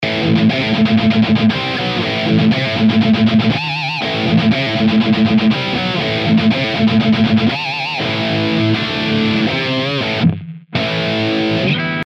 сделал тестик с реальной педалькой и плагом от онкеля тон на минимум ,драйв на 1/4